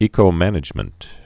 (ēkō-mănĭj-mənt, ĕkō-)